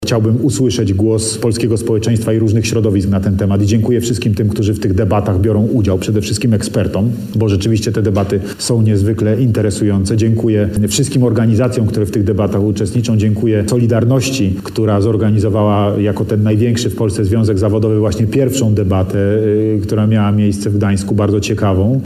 – Cały czas trwają prace nad nowym projektem Konstytucji Rzeczypospolitej Polskiej – powiedział na antenie Radia Warszawa Andrzej Duda. Prezydent w poranku „Siódma9” mówił między innymi o planowanych zmianach w zapisach Ustawy Zasadniczej.